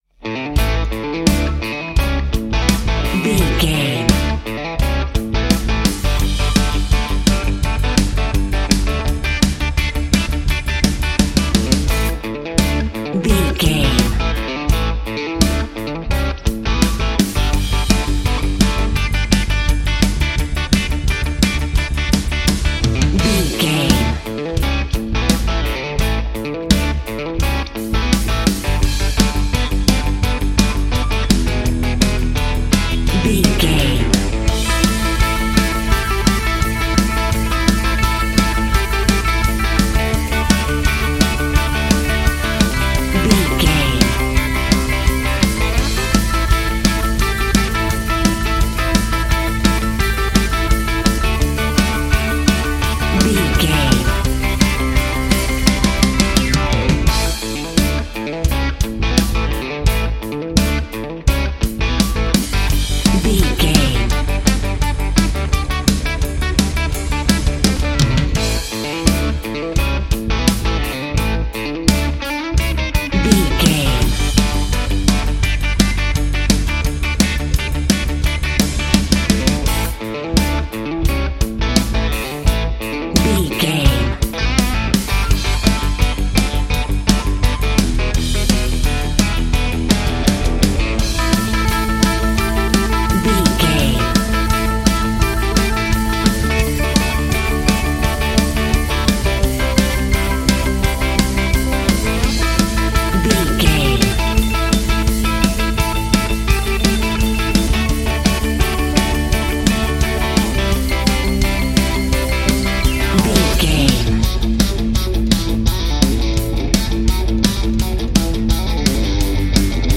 Epic / Action
Fast paced
Aeolian/Minor
bass guitar
electric guitar
drums
electric piano
aggressive
intense
groovy
driving
bouncy
energetic
funky
uplifting